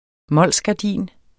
Udtale [ ˈmʌlˀs- ]